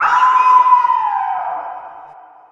贡献 ） 分类:游戏音效 您不可以覆盖此文件。
se_wolf.mp3